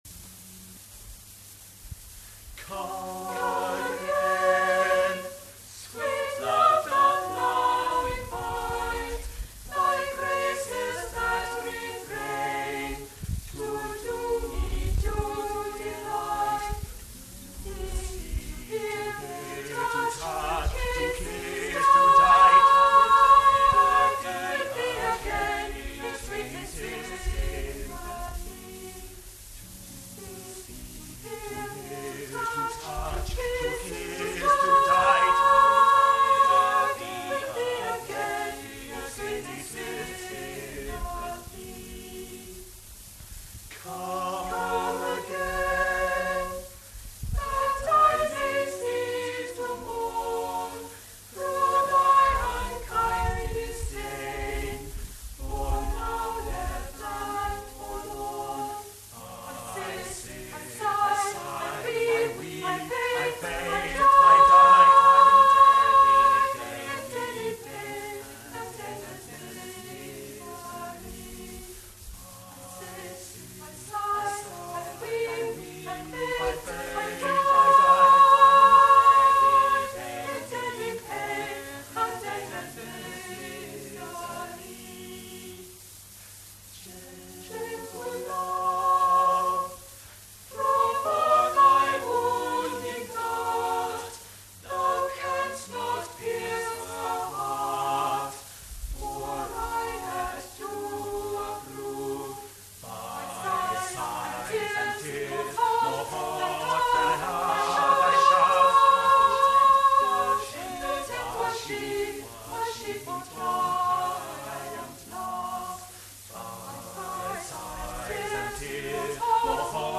Listen to members of Peterhouse Choir performing
Dowland's "Come Again" & Morley's "My Bonnie Lass" at the 1986 May Week Concert